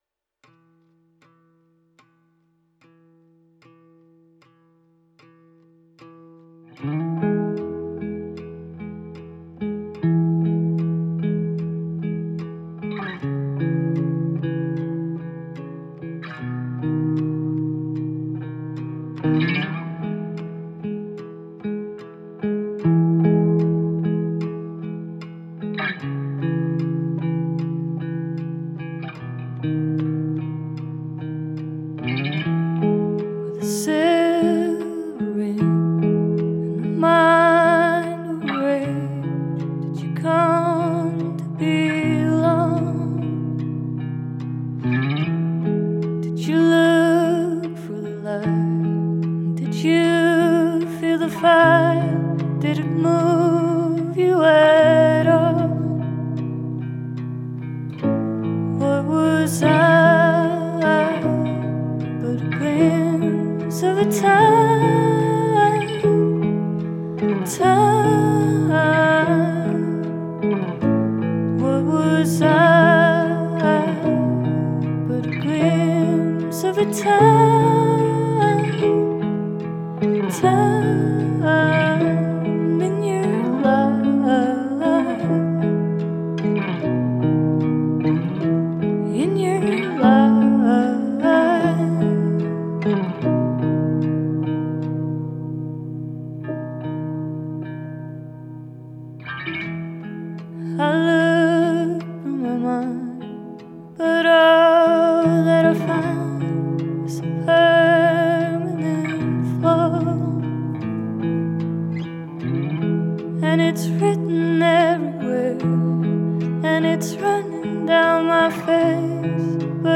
Danish singer/songwriter